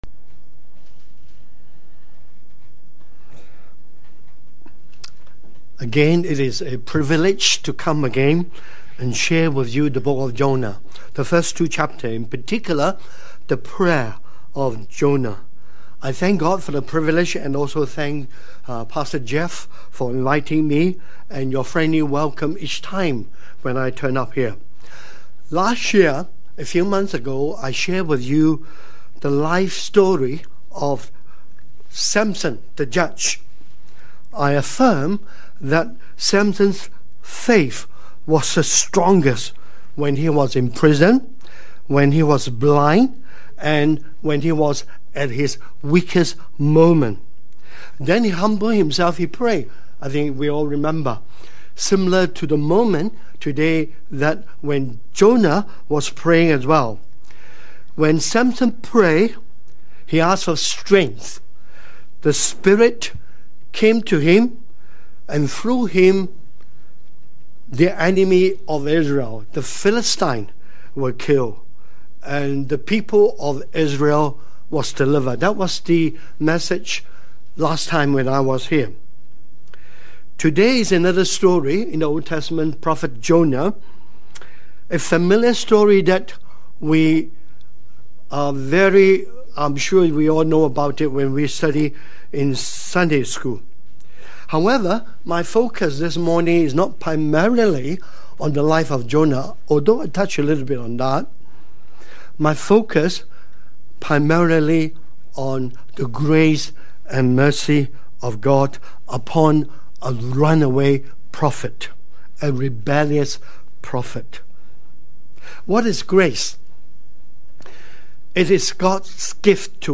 English Worship (LCK) - Jonah's Life: God's Grace & Mercy